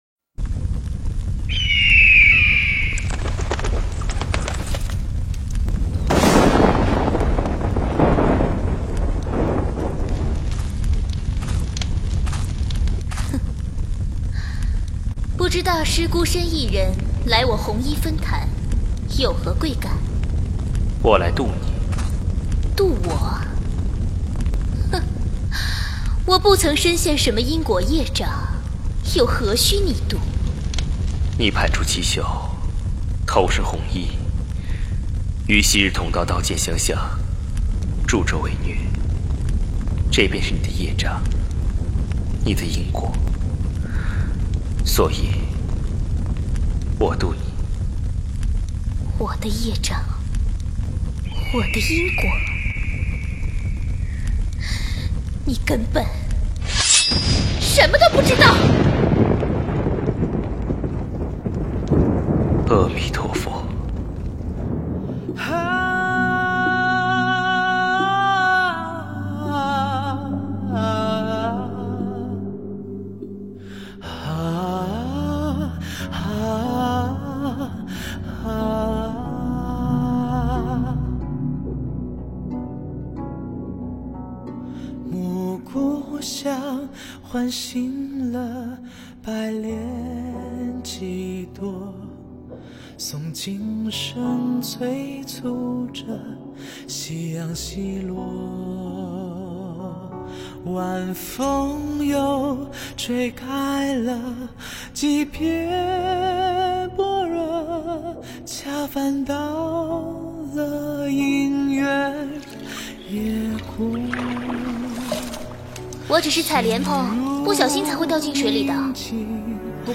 佛音 诵经 佛教音乐 返回列表 上一篇： 那一年、那一世 下一篇： 问佛 相关文章 心经(粤语